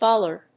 音節bol・lard 発音記号・読み方
/bάlɚd(米国英語), bˈɔlɑːd(英国英語)/